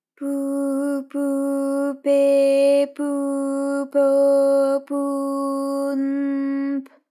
ALYS-DB-001-JPN - First Japanese UTAU vocal library of ALYS.
pu_pu_pe_pu_po_pu_n_p.wav